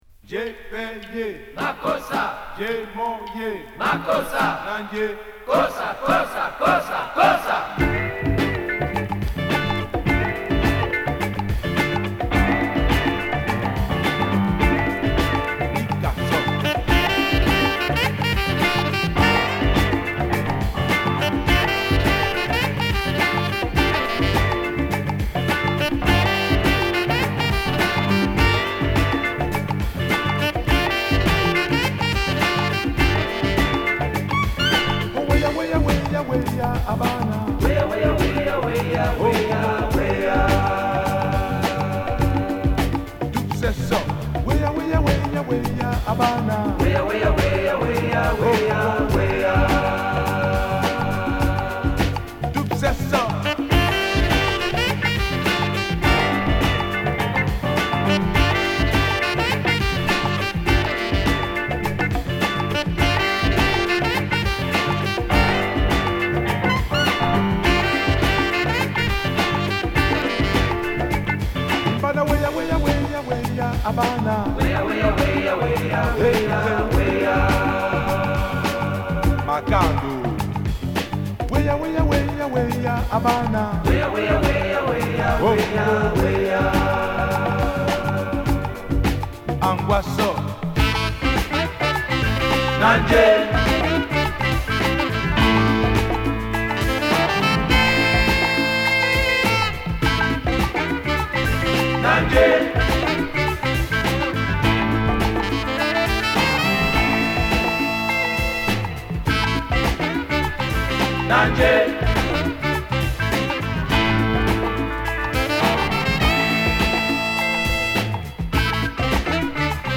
ド渋なアフロ・ジャズファンクを満載！！